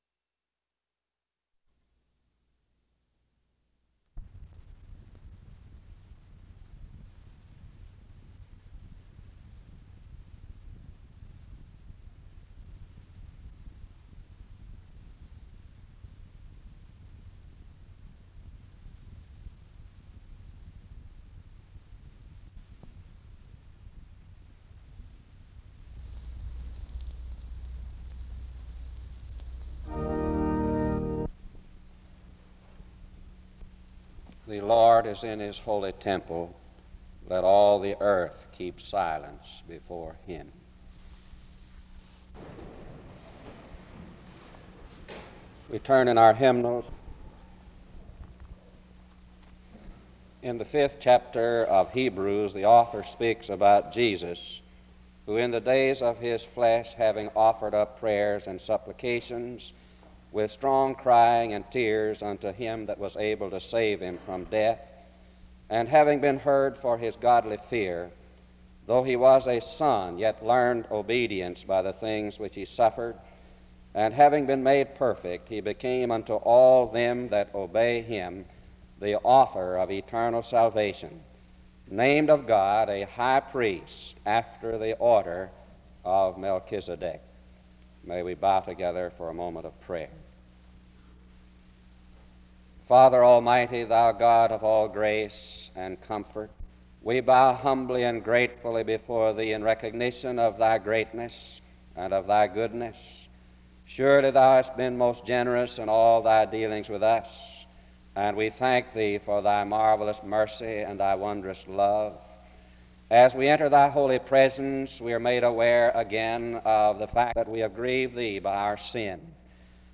In Collection: SEBTS Chapel and Special Event Recordings SEBTS Chapel and Special Event Recordings